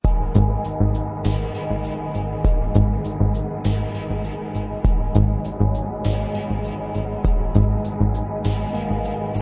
Tranquil ambient melody for relaxation and focus
ambient, background